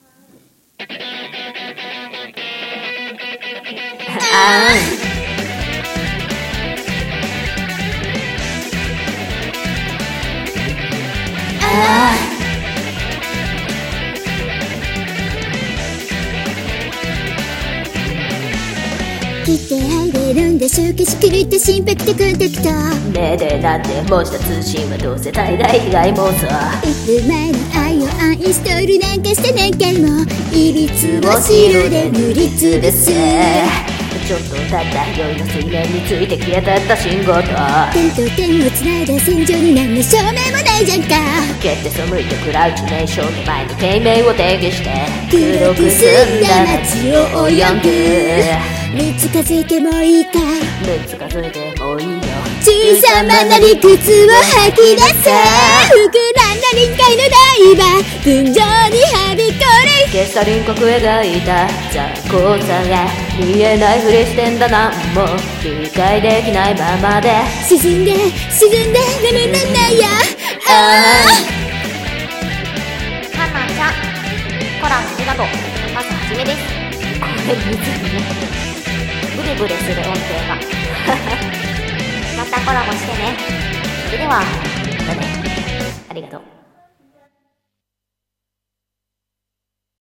何これムズいww｢理解されない ままで〜｣の音程間違えた🙏💦→重ねた低い方かな！(笑)